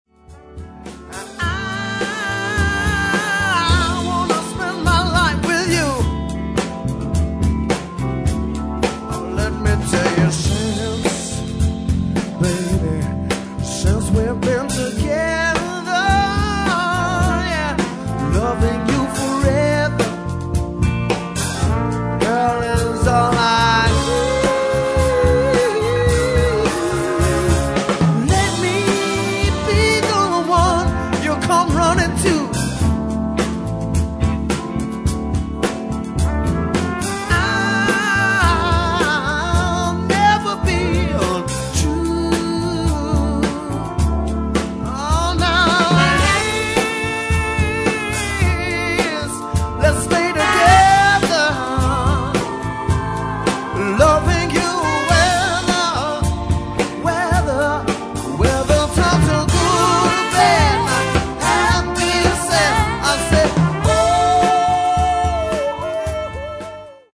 Vocalist / Guitarist / Harmonica (Massachusetts)